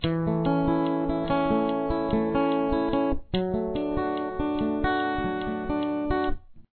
classic country song